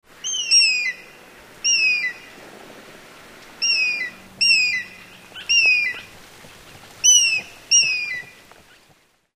Звуки орла
На этой странице собраны разнообразные звуки орла: от пронзительных криков до низкого клекота.
Крик орла nГолос орла nЗов орла nПеснь орла